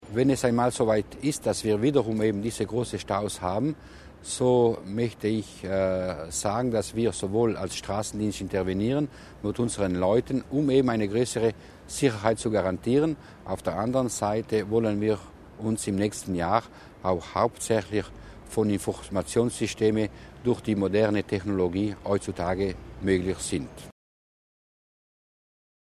Landesrat Mussner über die Maßnahmen für die Sicherheit